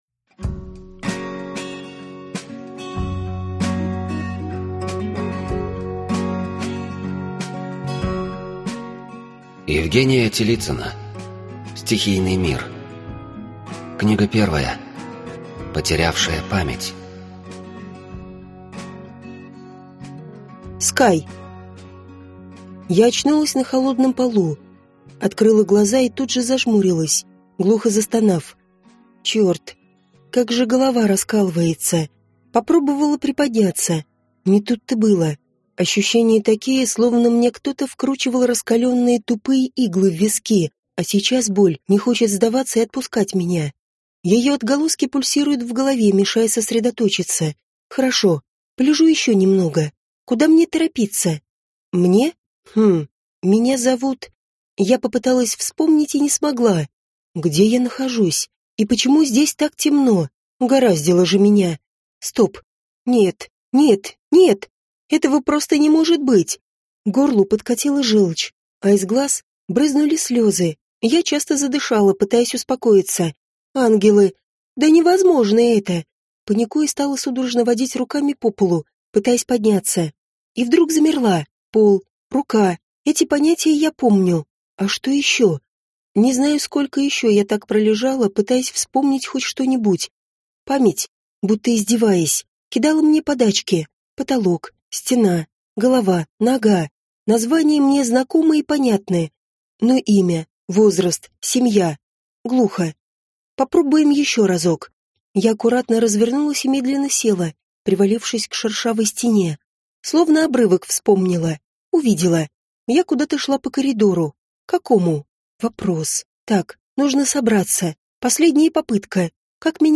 Аудиокнига Потерявшая память | Библиотека аудиокниг